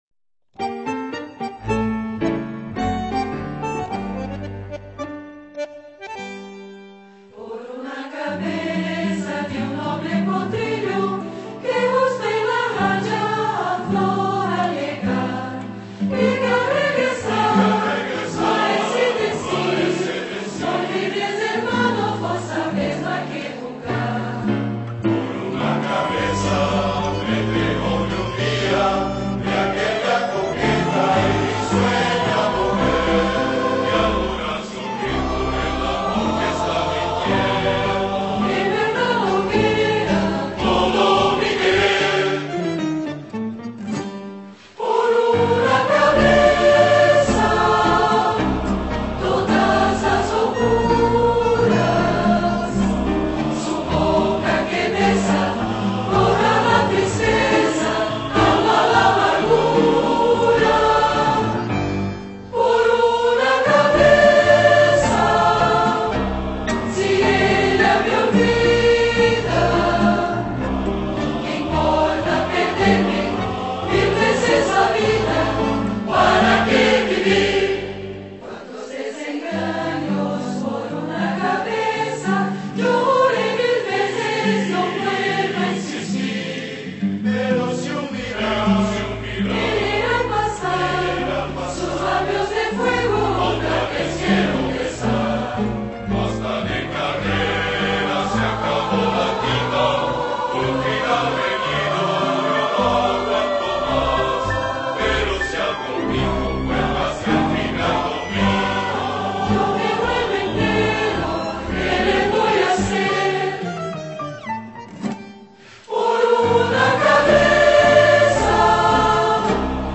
Pianistas